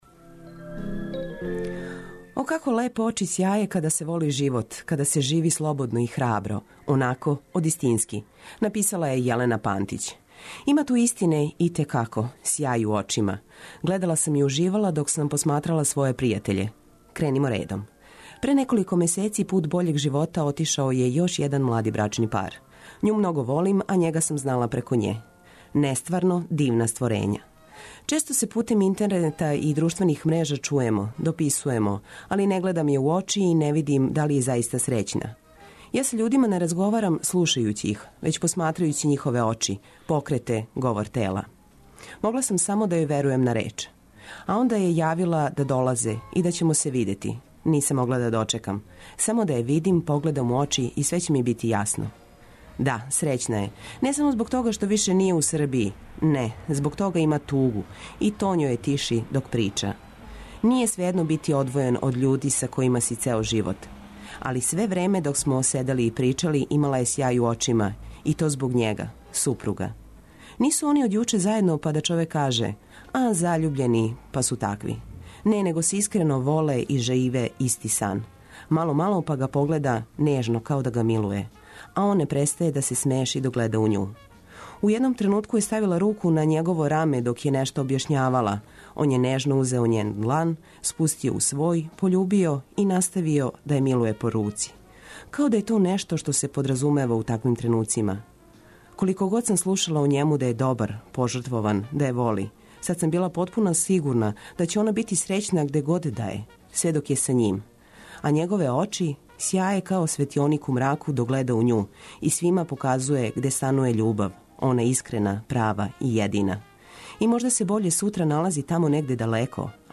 Уранићемо уз Цртицу 202, а затим прелазимо на сервисне, културне и спортске информације прошаране добром музиком и прилозима вредним Ваше пажње.